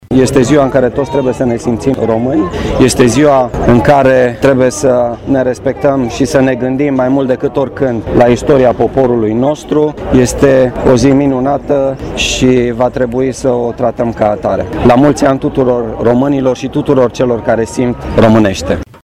Instituția Prefectului – Județul Brașov, în parteneriat cu Garnizoana Braşov, a organizat în Piaţa Tricolorului, o manifestare specială dedicată aniversării Zilei Drapelului Naţional al României.
Prefectul Marian Rasaliu: